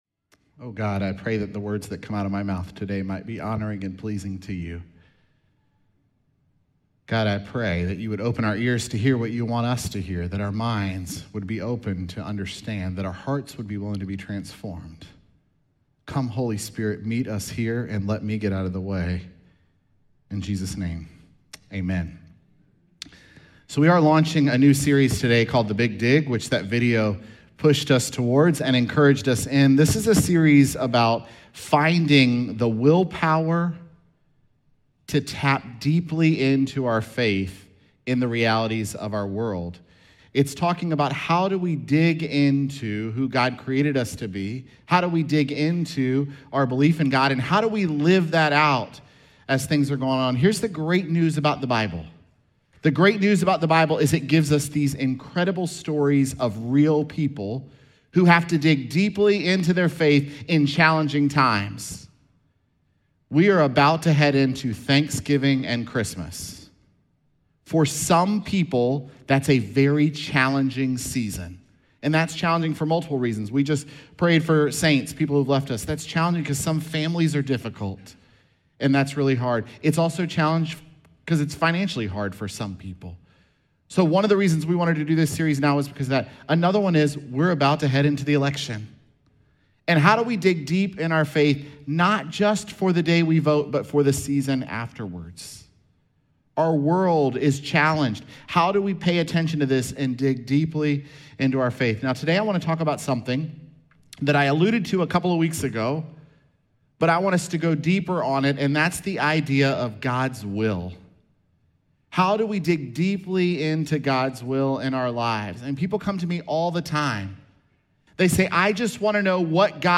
Sermons
Nov3SermonPodcast.mp3